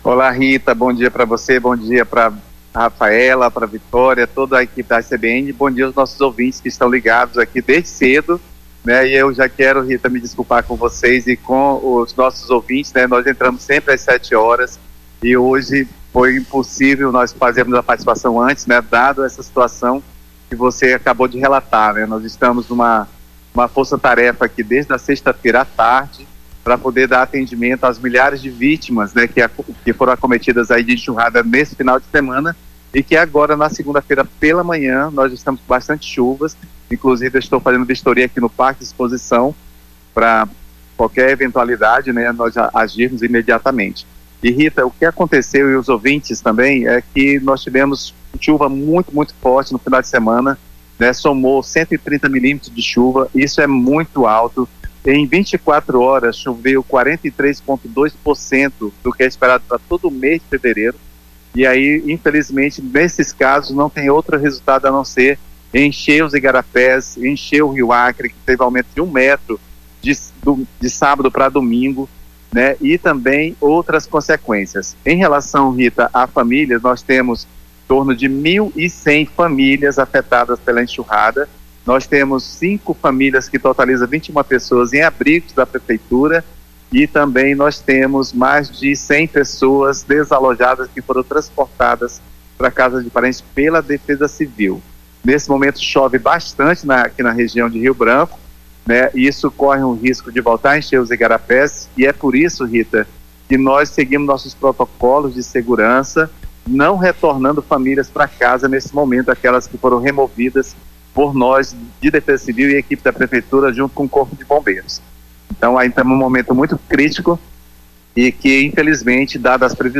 Coordenador da Defesa Civil de Rio Branco explica a situação dos rios, igarapés e a previsão do tempo para essa segunda-feira (24)